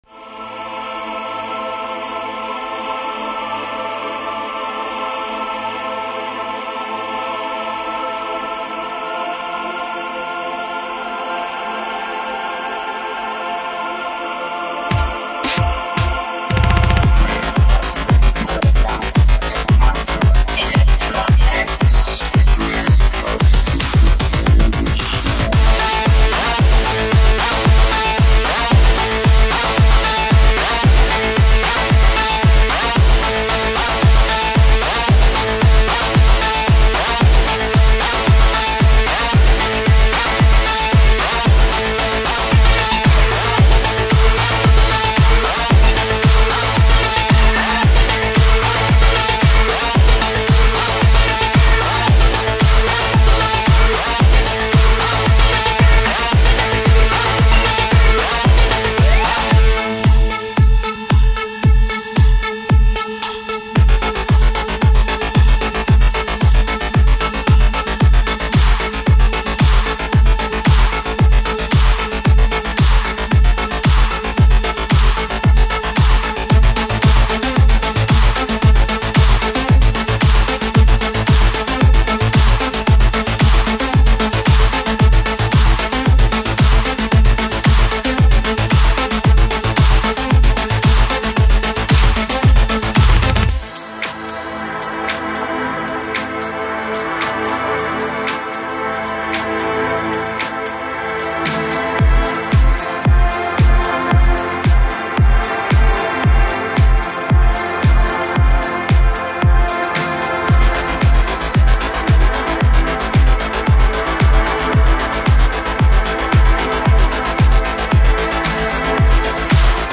Альбом: Dark Techno